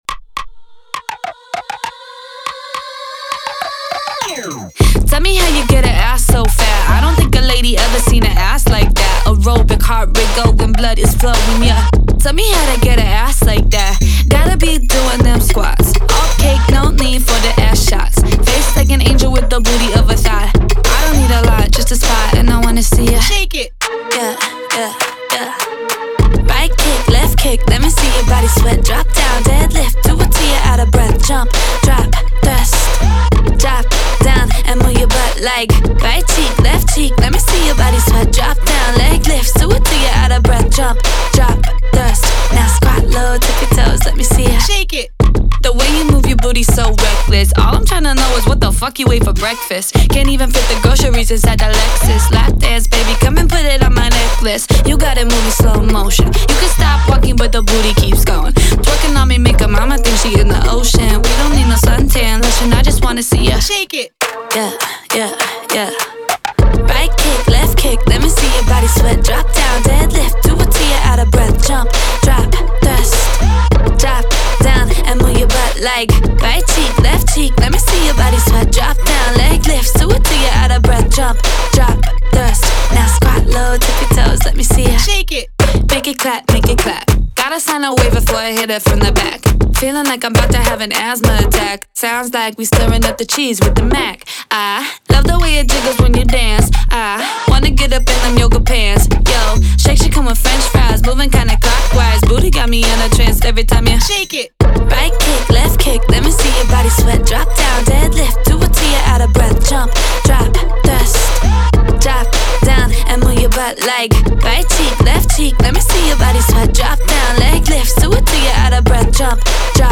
Hip_Hop-1.mp3